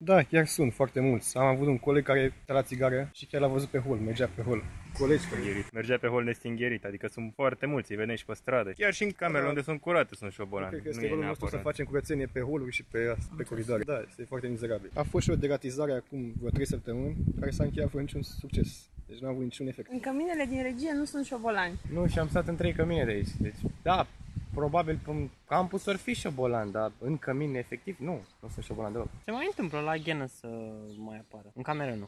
O parte sunt revoltați, în timp ce alții spun că nu există rozătoare în zonă: